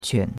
quan3.mp3